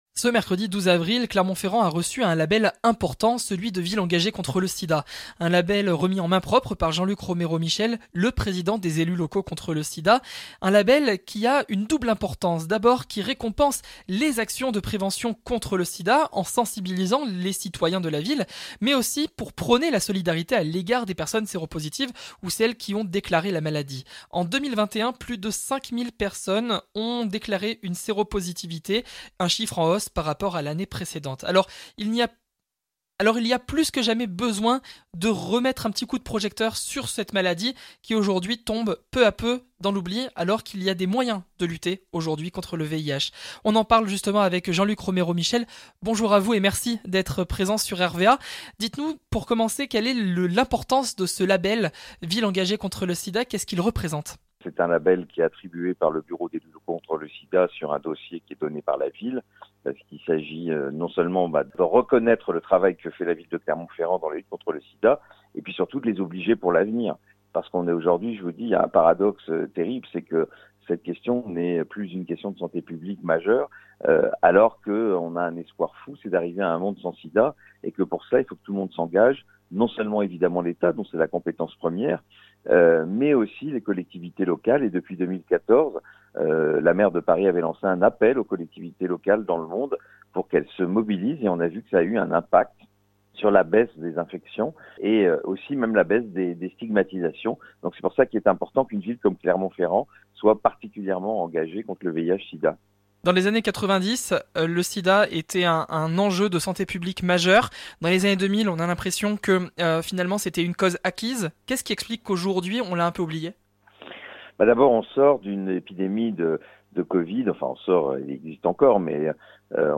itv-jean-luc-romero-michel-ville-engagee-conte-le-sida-wm-5087.mp3